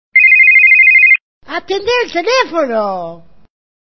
забавные
Звонок стационарного телефона и забавный бабулин голос